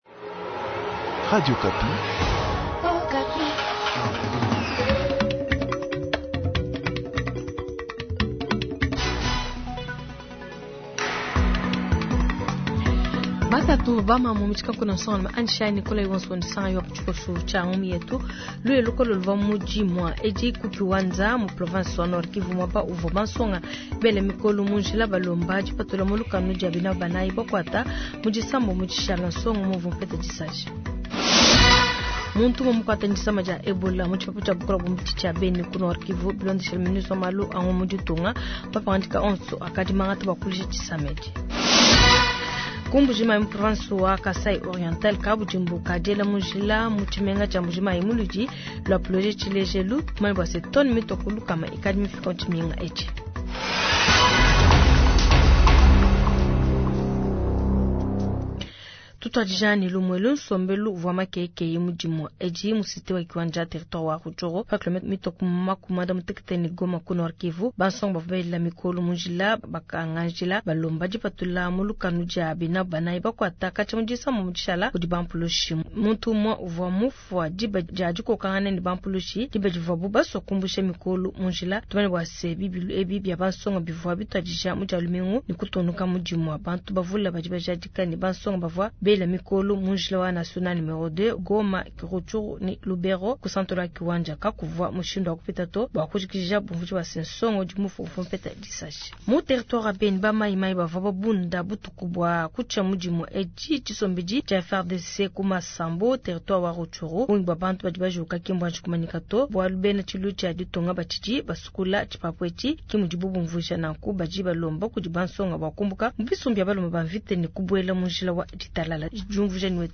Journal soir